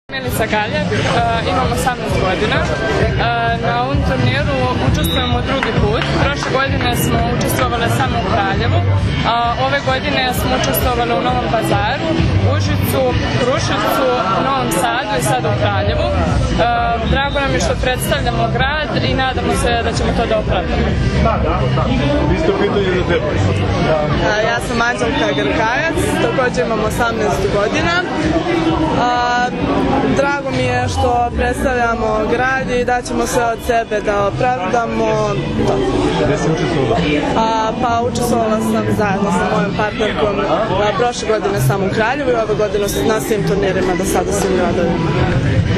Večeras je u Kraljevu održana konferencija za novinare